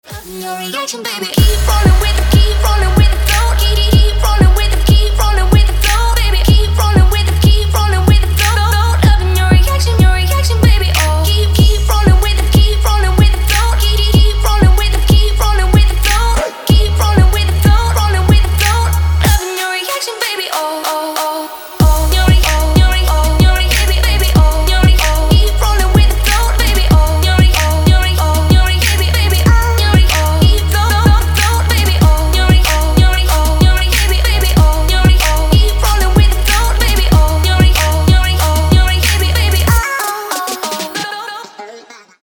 • Качество: 192, Stereo
Electronic
EDM
Trap
club
Bass